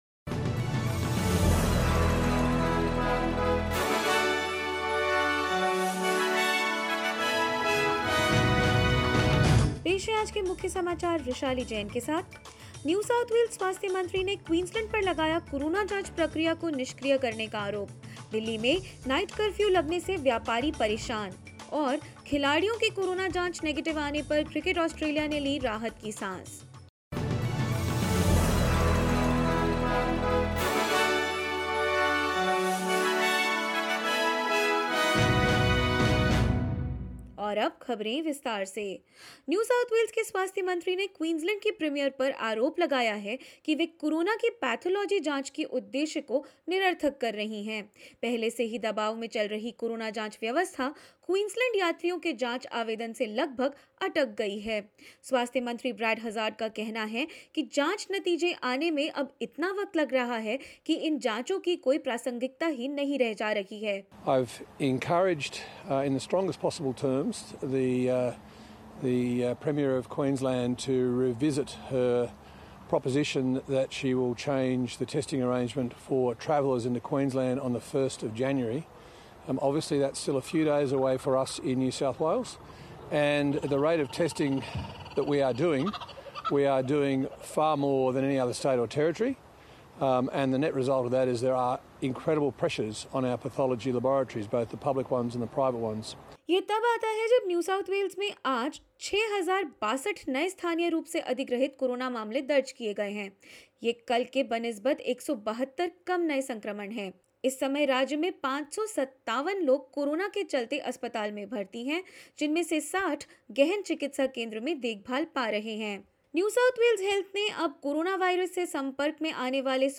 In this latest SBS Hindi news bulletin: NSW health minister Brad Hazzard accuses Queensland for testing delays, as Queensland changes its travel requirements; Cricket Australia breathes a sigh of relief after all players from Australia and England returned negative tests to COVID-19 and more.